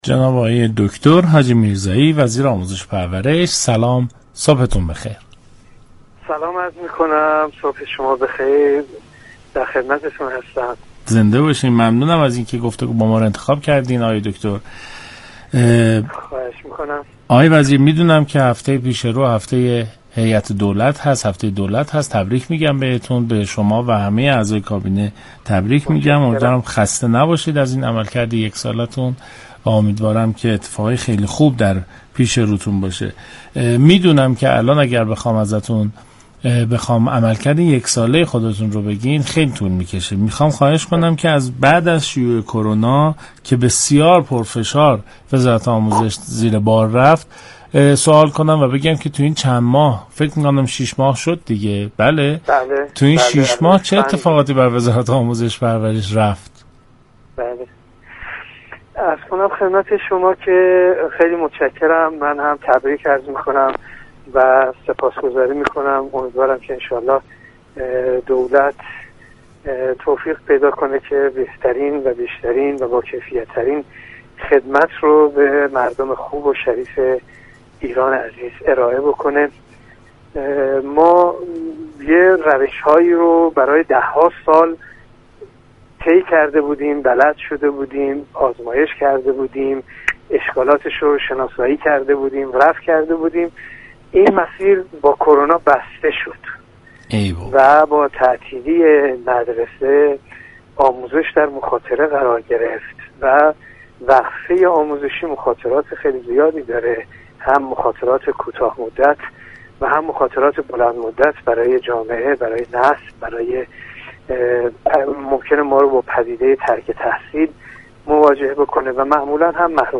دكتر محسن حاجی میرزایی وزیر آموزش و پرورش در سلام صبح بخیر گفت: در شرایط بحرانی اقشار آسیب پذیر معمولا بیشتر در معرض خطر قرار می گیرند.